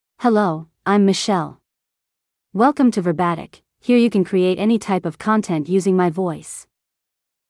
FemaleEnglish (United States)
Michelle is a female AI voice for English (United States).
Voice sample
Female
Michelle delivers clear pronunciation with authentic United States English intonation, making your content sound professionally produced.